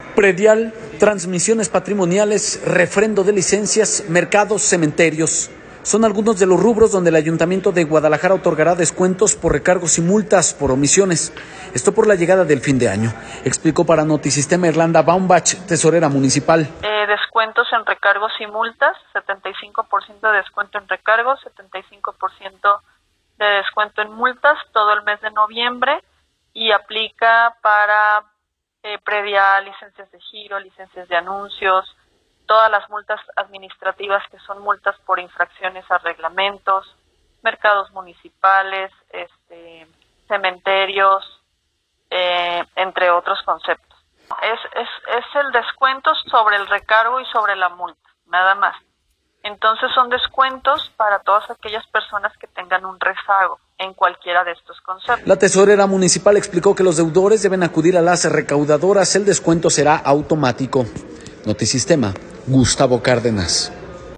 Predial, transmisiones patrimoniales, refrendo de licencias, mercados, cementerios, son algunos de los rubros donde el Ayuntamiento de Guadalajara otorgará descuentos por recargos y multas por omisiones, esto por la llegada de fin de año, explicó para Notisistema Irlanda Baumbach, Tesorera municipal.